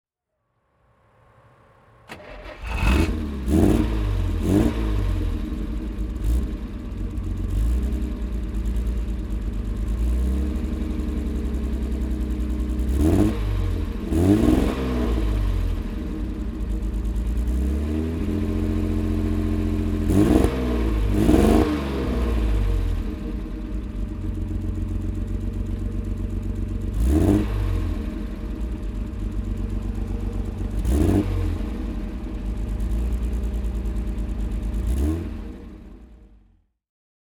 Maserati Sebring (1962) - Starten und Leerlauf
Maserati_Sebring_1962.mp3